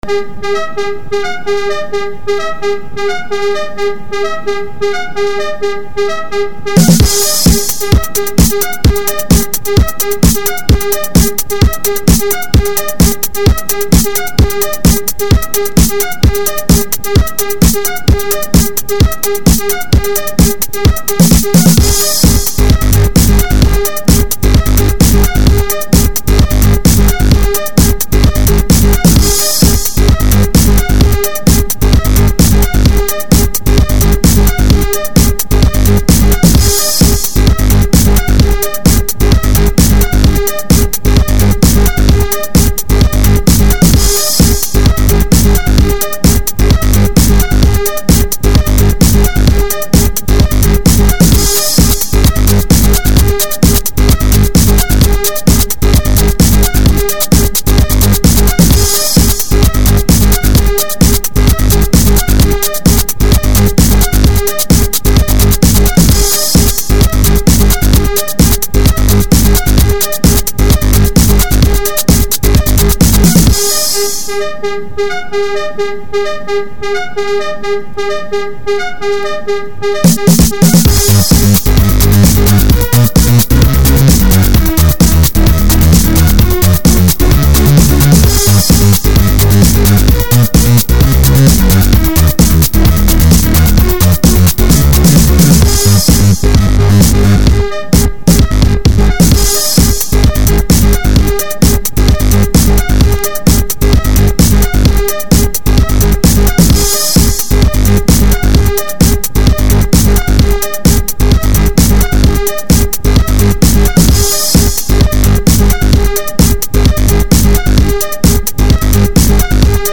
Категория: Клубная музыка